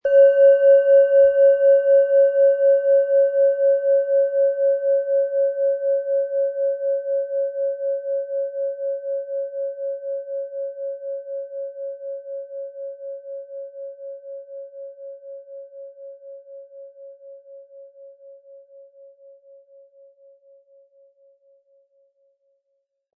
Tibetische Universal-Klangschale, Ø 11,8 cm, 260-320 Gramm, mit Klöppel
Wir haben die Klangschale beim Aufnehmen getestet und das persönliche Empfinden, dass sie alle Körperregionen nahezu gleich deutlich zum Schwingen bringt.
Im Audio-Player - Jetzt reinhören hören Sie genau den Original-Ton der angebotenen Schale.
Mit einem sanften Anspiel "zaubern" Sie aus der mit dem beigelegten Klöppel harmonische Töne.